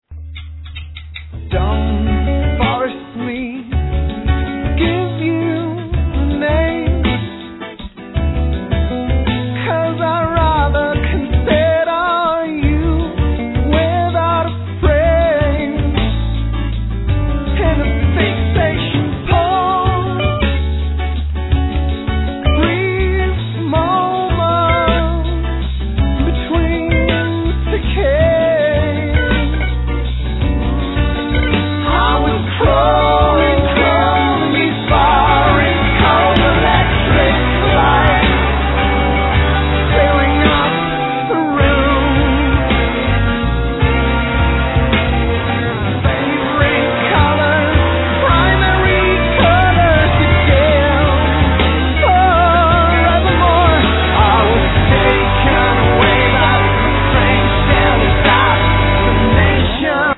(Lead Vocals, Trumpet, Flugelhorn)
(Drums, Backing Vocals)